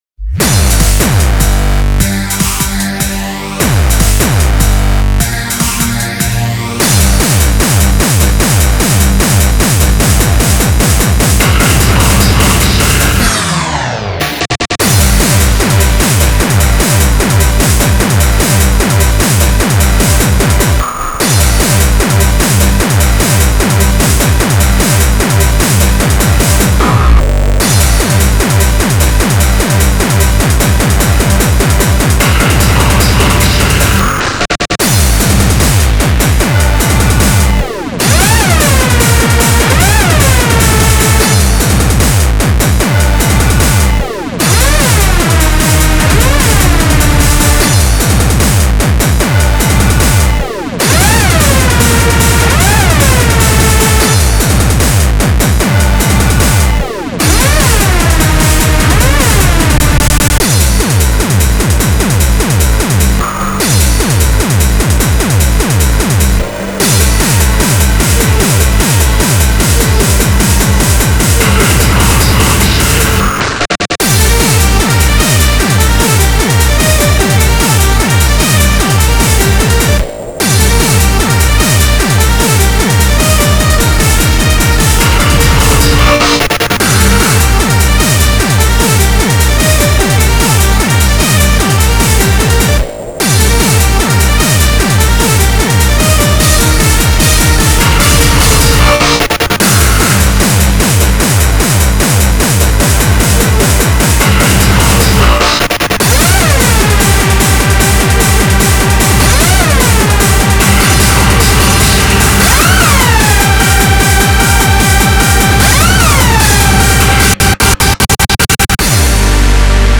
BPM75-300
Audio QualityPerfect (High Quality)
Genre: Freeform Hardcore